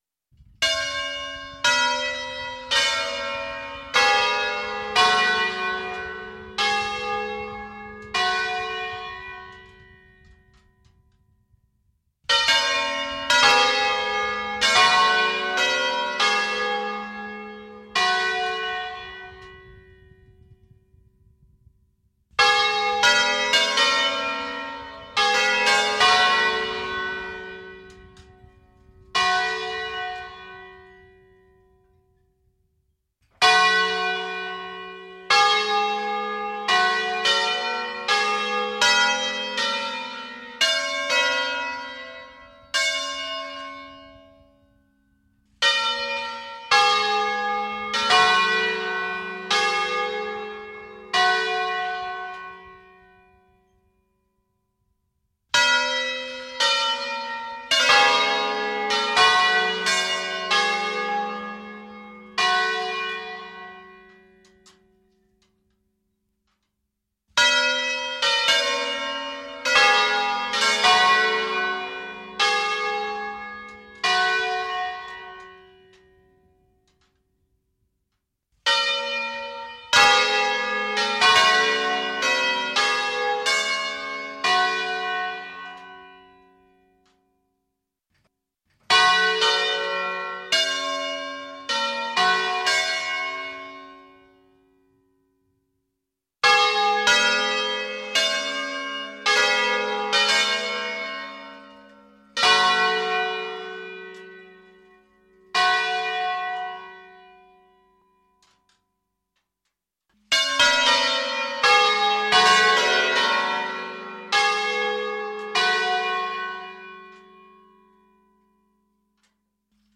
Campane-di-Baresi-a-concerto.mp3